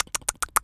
mouse_eating_04.wav